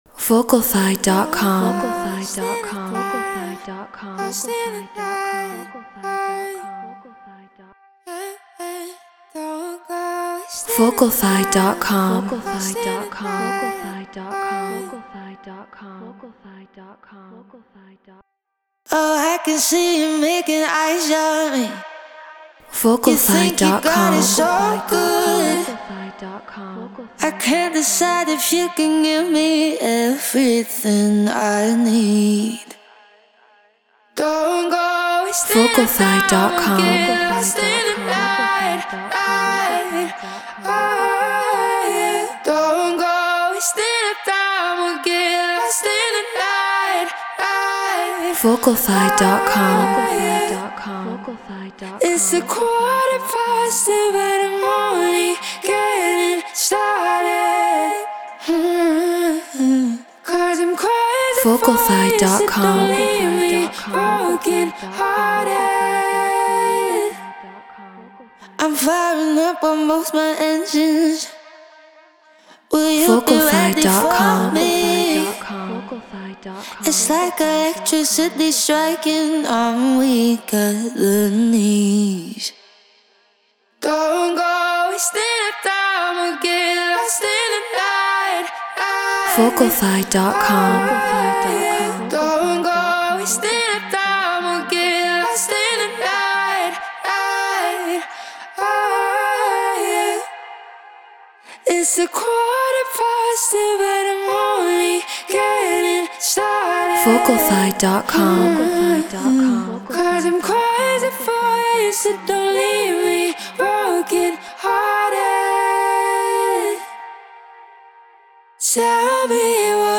Get Royalty Free Vocals.
Non-Exclusive Vocal.